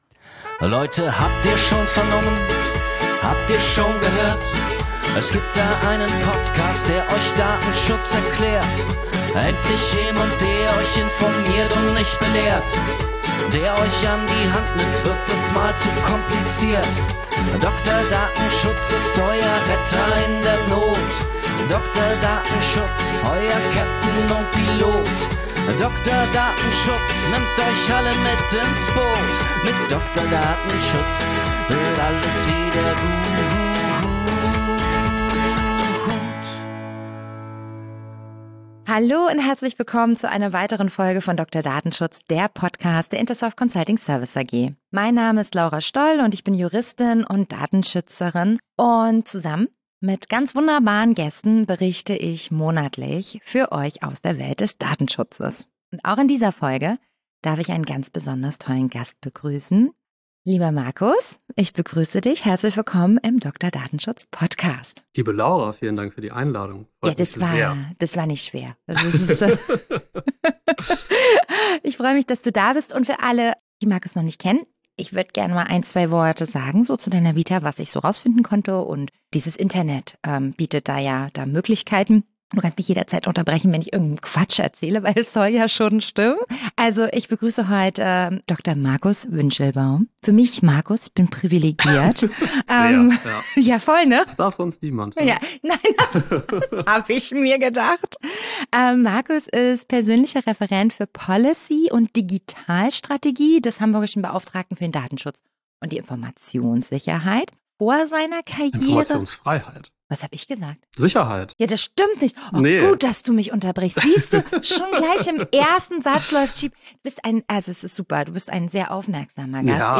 Juristen und Datenschutzberater, sind die Podcast-Stimmen von Dr. Datenschutz und geben Einblicke in die Datenschutzwelt.